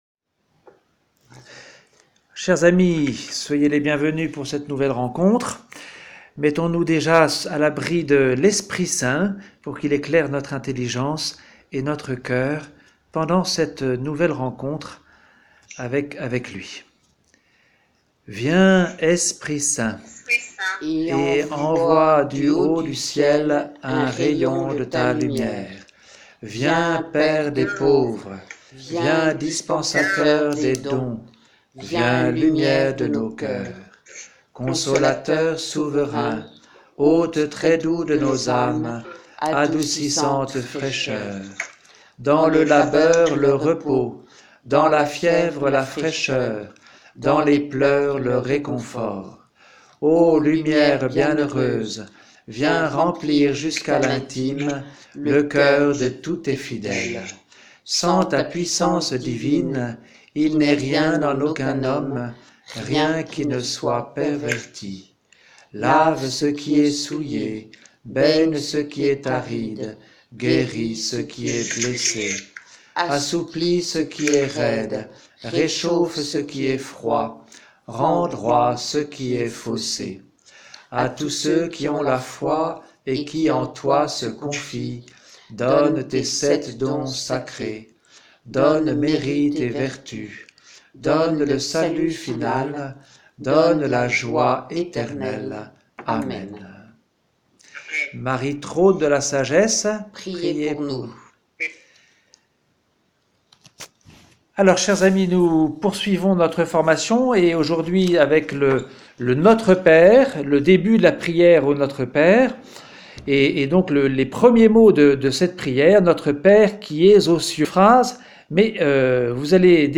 Enregistrements du cours